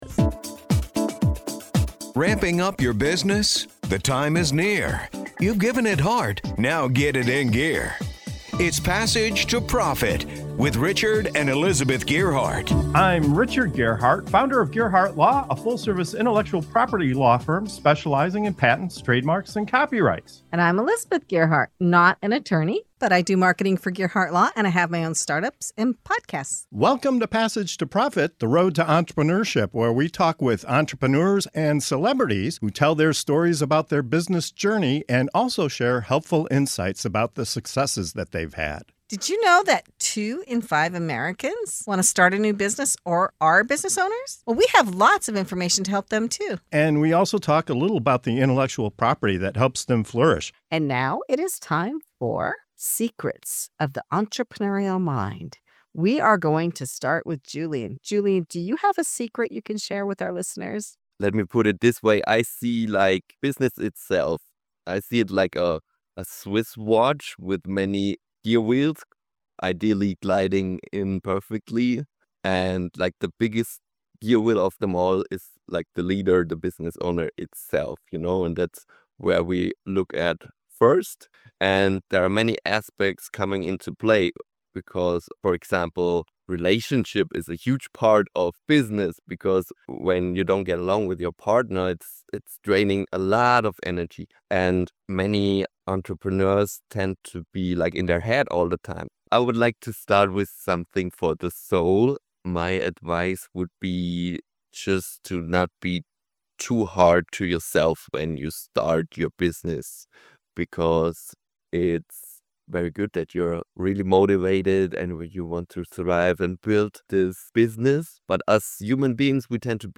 In this segment of “Secrets of the Entrepreneurial Mind” on the Passage to Profit Show, we uncover the wisdom, courage, and resilience it takes to succeed in business. From embracing failure and self-belief to growing as a leader and confidently saying "no," our speakers share powerful insights and practical tips for navigating the challenges of entrepreneurship.